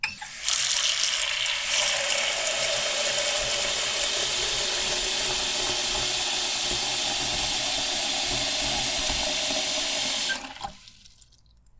鍋に水を入れる１
put_water_in_pot1.mp3